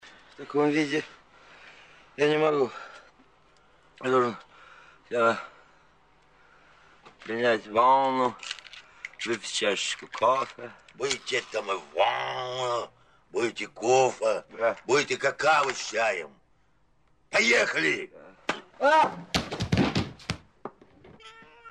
Звуки и фразы из советских фильмов: принять ванну, выпить чашечку кофе